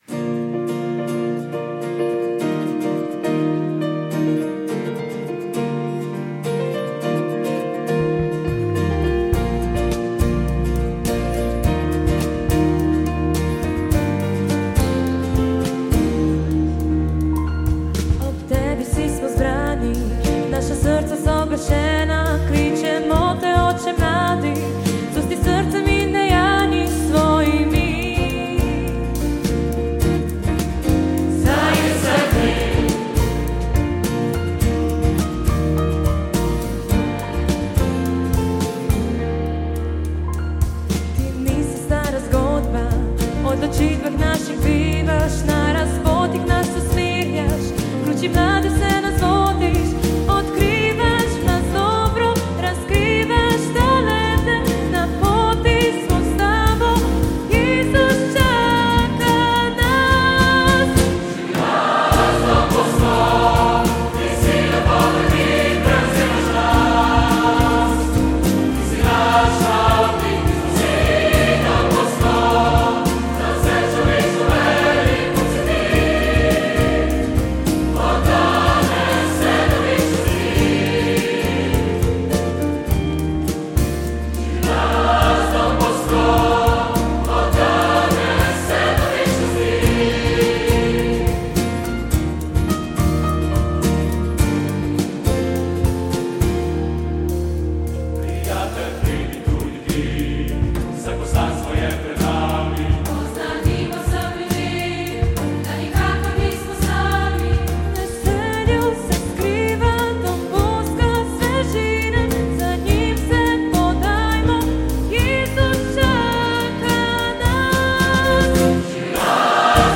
V izvedbi Župnijskega pevskega zbora Ljubljana Rakovnik